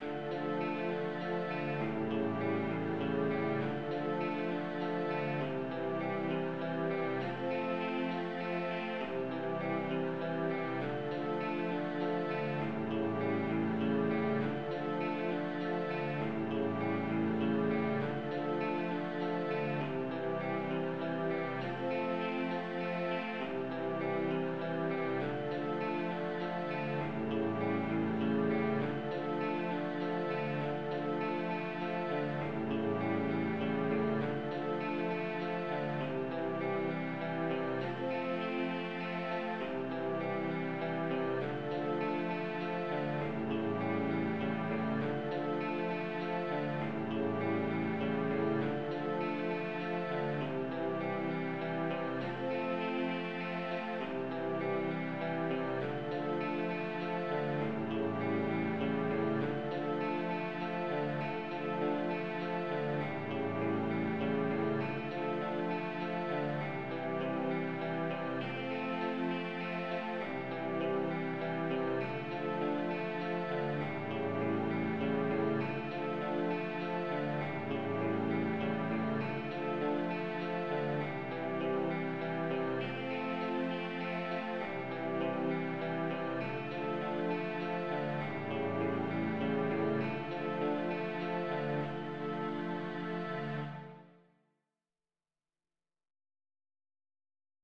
On a first track, strings are playing the chords of that classical theme. On the second track, the same chords are arpeggiated in several ways.